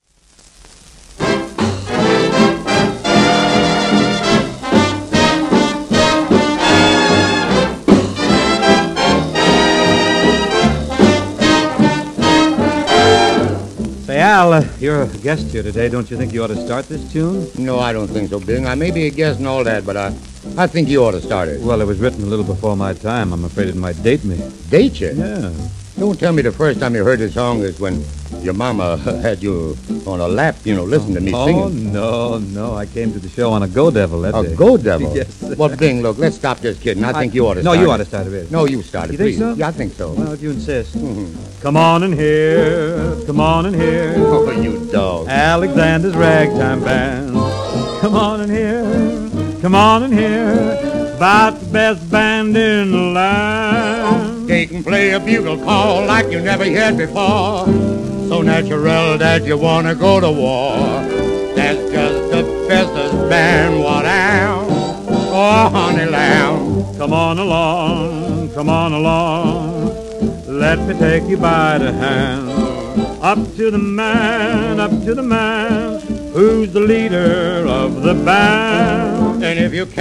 当時の米国を代表するエンターテイナー２人の共演盤